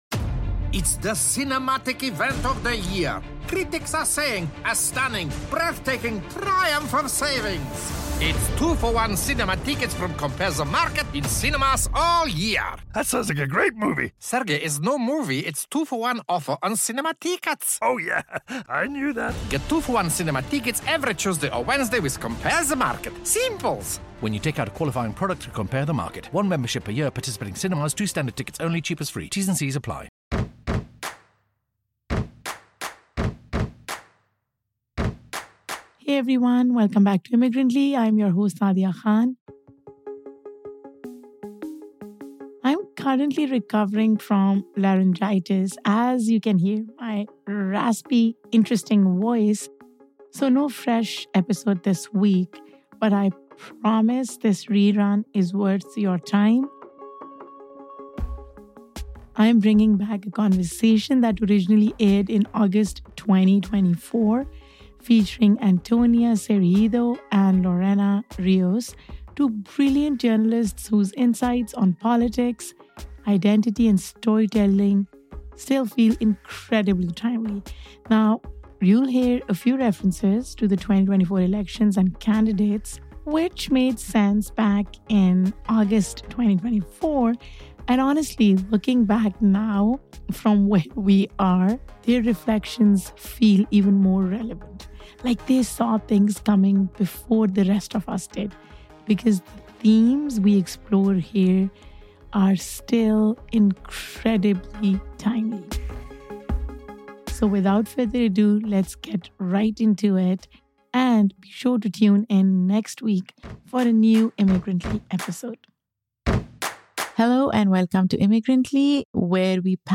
Originally recorded in the lead-up to the 2024 elections, this conversation explores politics, identity, and the storytelling power that shapes public discourse. Though some references to candidates and timelines reflect the moment, the themes remain strikingly relevant today.